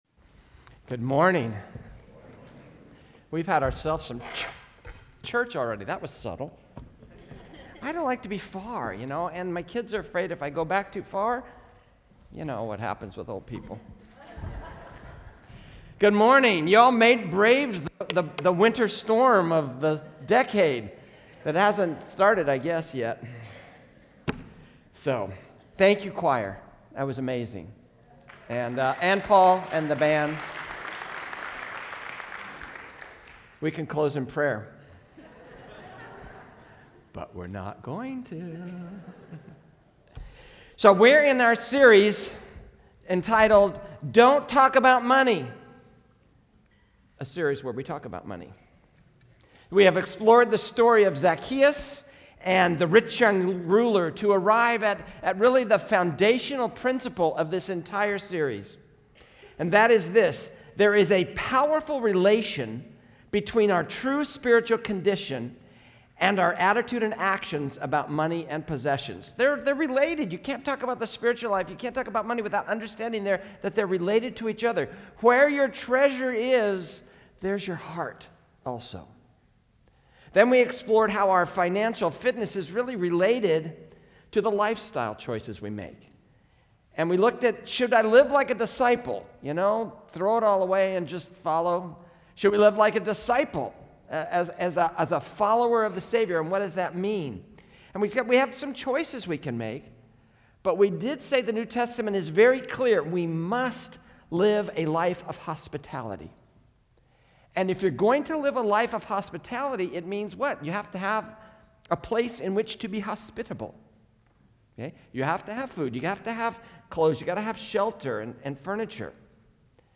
A message from the series "Don't Talk About Money."